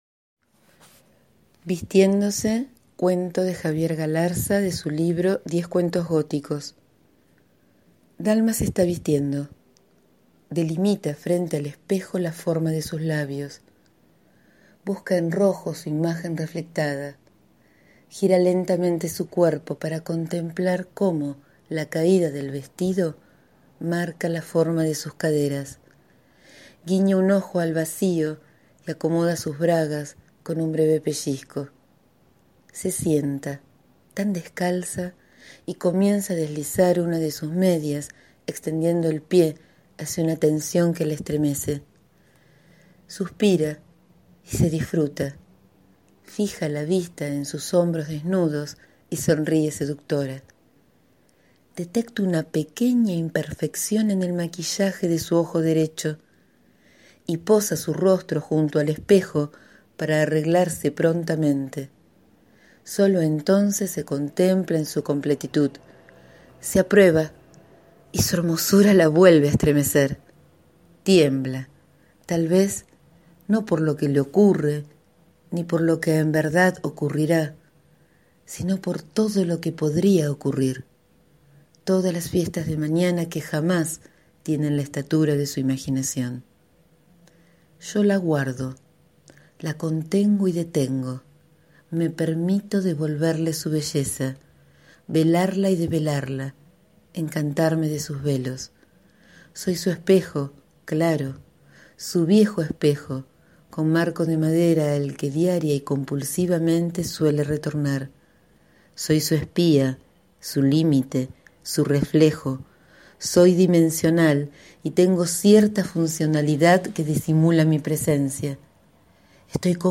Hoy elijo leer uno de esos diez relatos: «Vistiéndose»
En el audio queda el texto en mi voz,  que la belleza de este escrito los alcance.